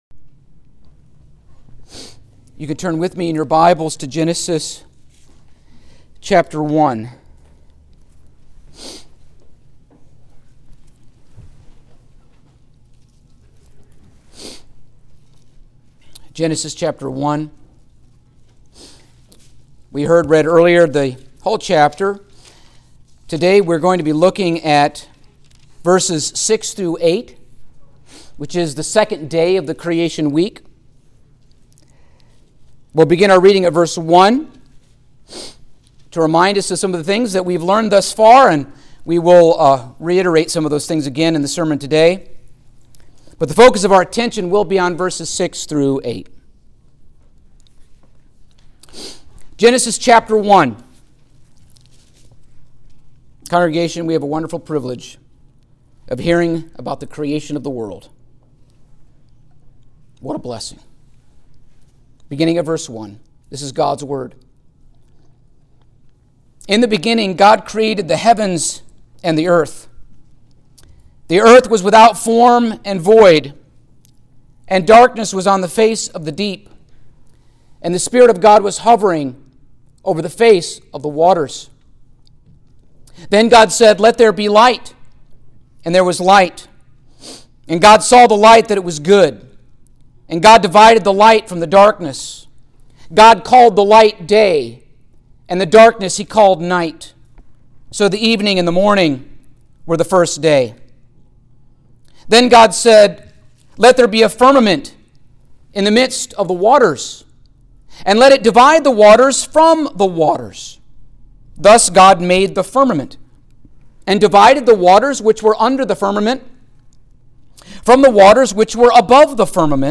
Genesis Passage: Genesis 1:6-8 Service Type: Sunday Morning Topics